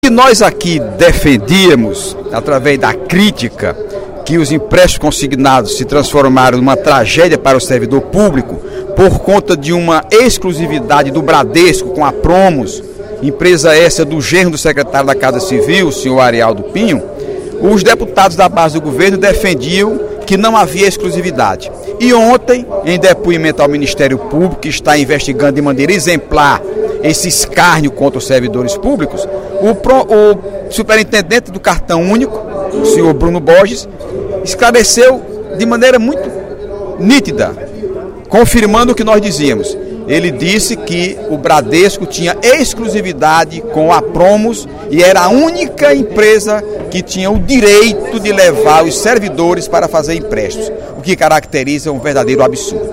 O deputado Heitor Férrer (PDT) registrou na tribuna, nesta quinta-feira (14/06), que estava certo quando denunciou a exclusividade do Bradesco com a Promus para concessão de empréstimos consignados aos servidores do Estado.